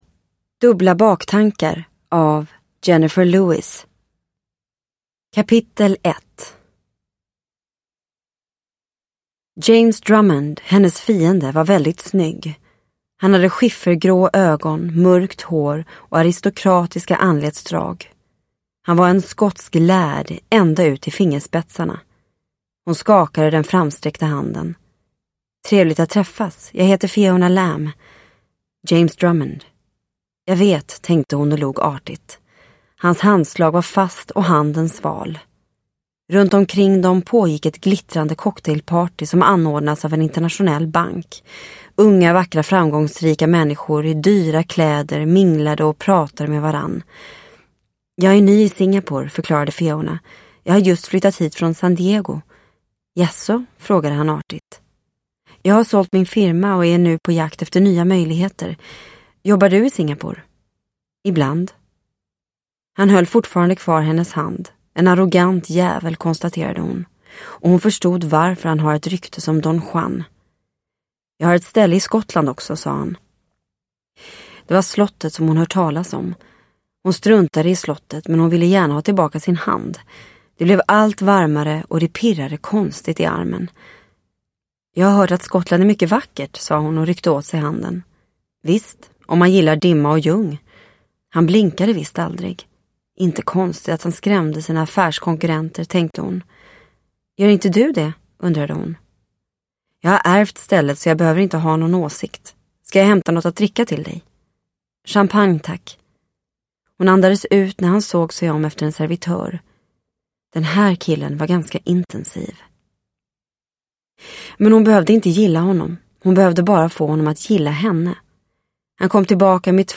Dubbla baktankar – Ljudbok – Laddas ner